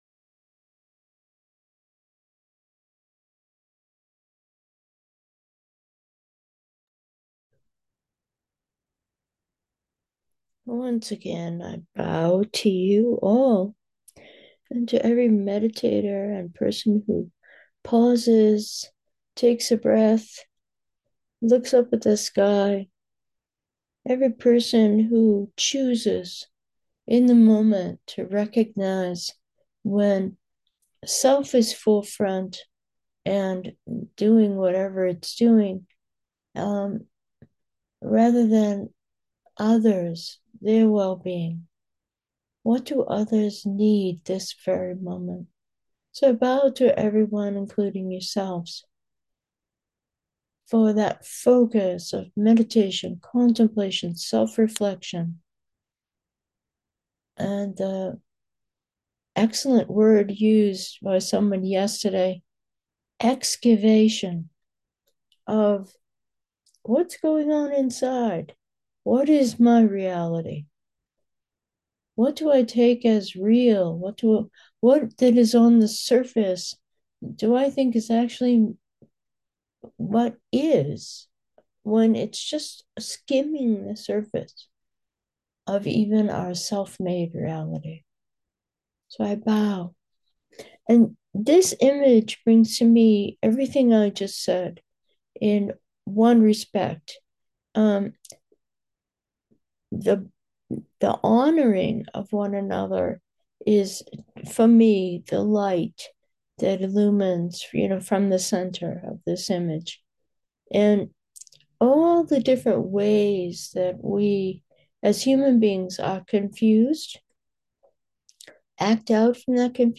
Meditation: review 1, Presence-Nowness